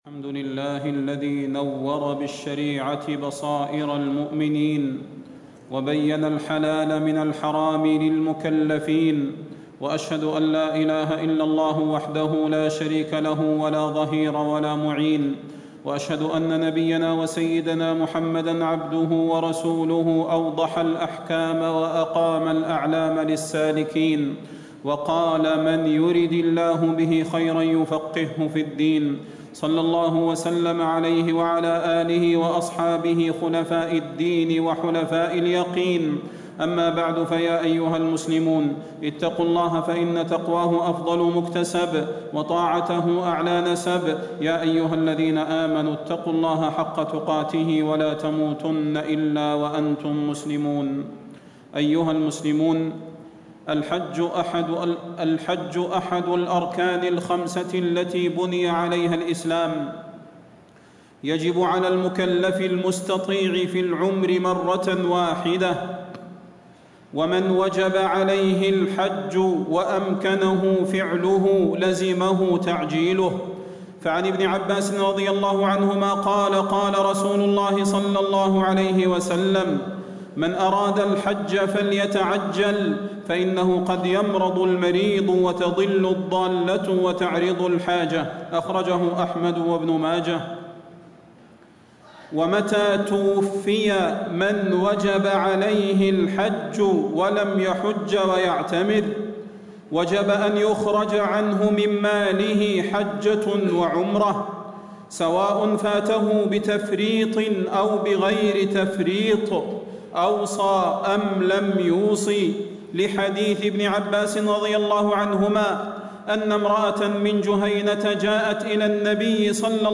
تاريخ النشر ٢٠ ذو القعدة ١٤٣٦ هـ المكان: المسجد النبوي الشيخ: فضيلة الشيخ د. صلاح بن محمد البدير فضيلة الشيخ د. صلاح بن محمد البدير أحكام في الحج